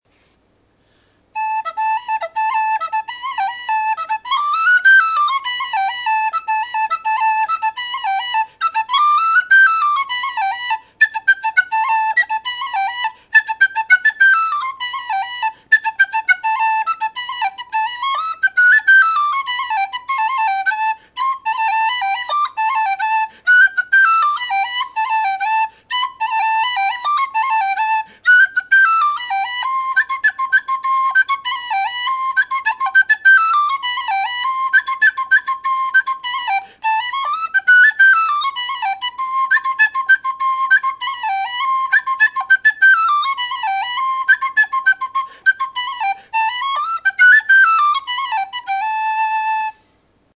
Whistle Reviewed: Overton non-tunable Soprano D
Construction: Aluminum body with aluminum fipple
Sound clip of the whistle:
Tone: Very slightly reedy, with a complex chiff. Also, the sound wasn’t as harsh as some loud aluminum soprano whistles. It’s definitely a bit sweeter, though the tone is still quite strong.
Volume: Above average, but with a wide variance (though this does change the pitch), and increasing dramatically in the 2nd octave, especially in the top end.